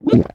Minecraft Version Minecraft Version 25w18a Latest Release | Latest Snapshot 25w18a / assets / minecraft / sounds / entity / witch / drink4.ogg Compare With Compare With Latest Release | Latest Snapshot
drink4.ogg